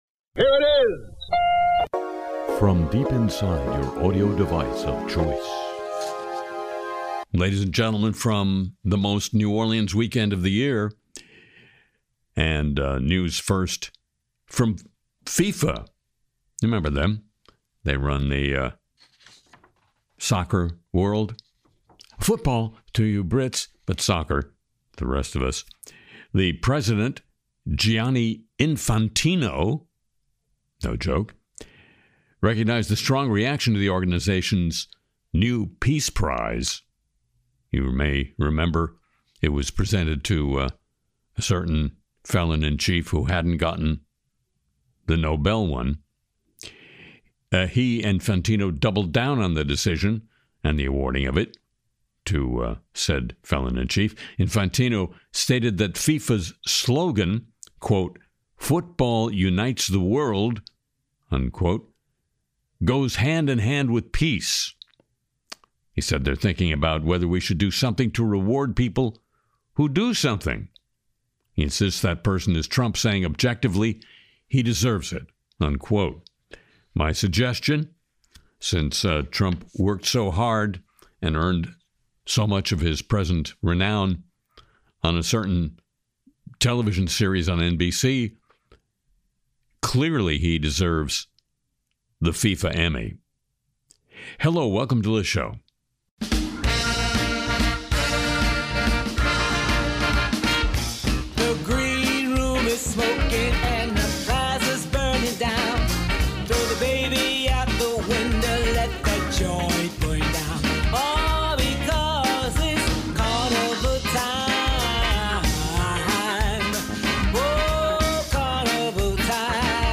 Le Show Le Show For The Week Of February 15, 2026 Play episode February 15 55 mins Bookmarks Episode Description On this week’s edition of Le Show, Harry brings us regular features like News of the Godly, News of Crypto-Winter, News of Smart World, News of A.I., Truth Social Audio with Donald Trump, News of the Atom, News of Musk Love, The Apologies of the Week, and News of the Olympic Movement. There’s also great music, including a new original piece and great Carnival themed tunes.